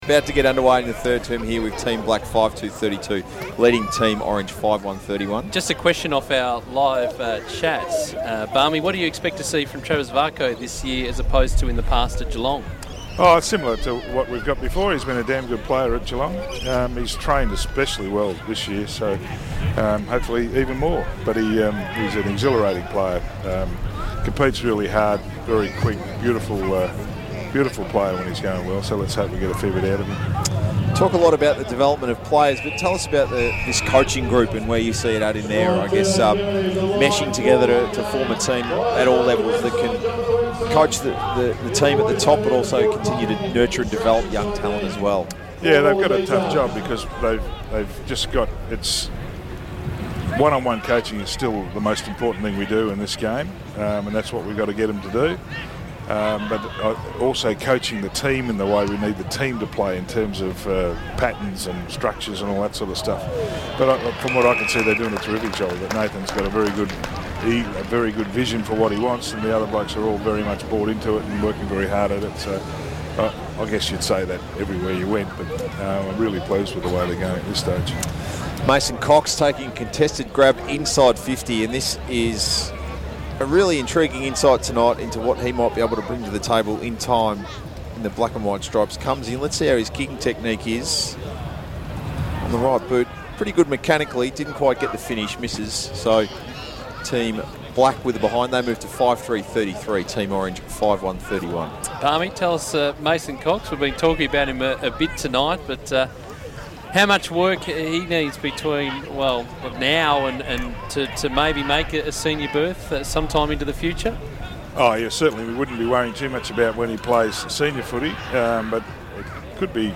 Listen to Collingwood Radio's commentary of the Magpies' first intra-club practice match of 2015 on Thursday 19 February.